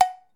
Cowbell_OS_4
Bell Cartoon Cow Cowbell Ding Dong H4n Ring sound effect free sound royalty free Movies & TV